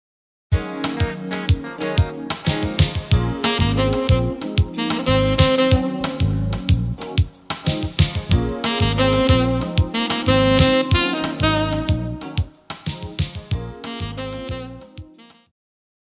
Jazz
Band
Instrumental
Smooth Jazz
Only backing